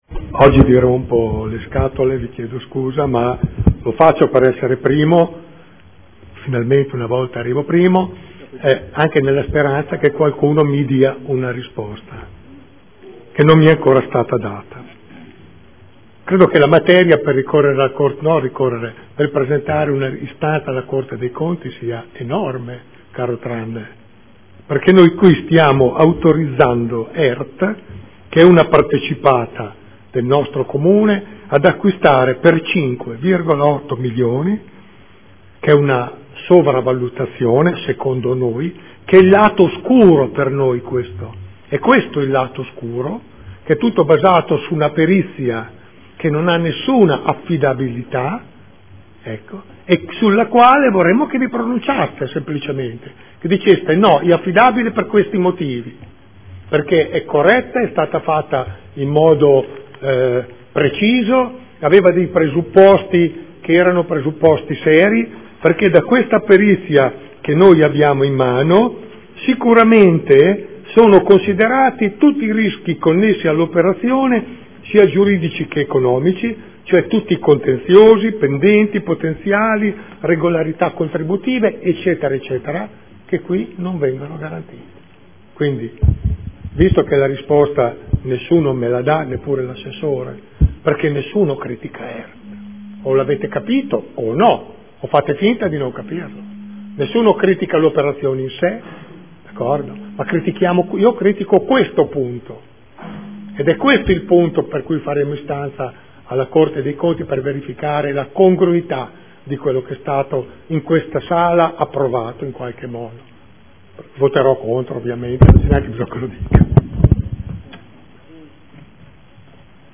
Seduta del 23/01/2014 Dichiarazione di Voto. Adesione del Comune di Bologna a Emilia Romagna Teatro Fondazione in qualità di socio fondatore necessario.